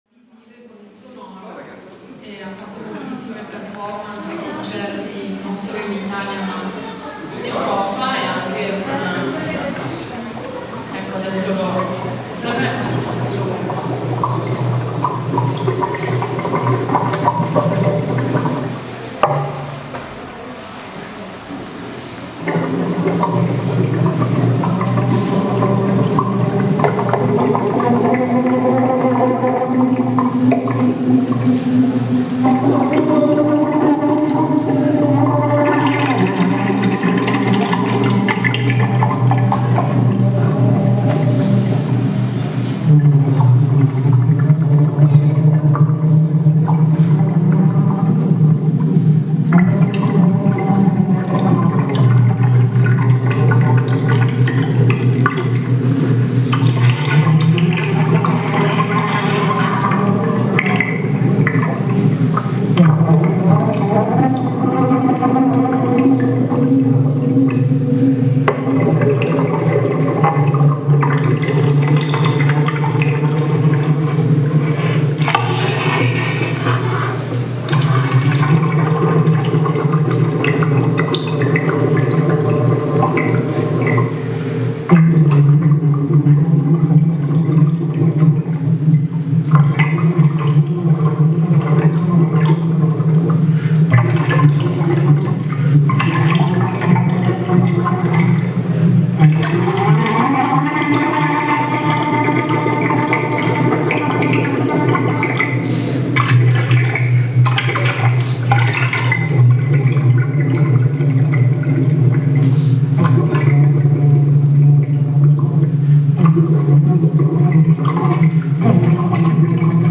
Suono dell'acqua Performance in occasione di Geode (2012), Genova.
S(U)ONO DELL'ACQUA.mp3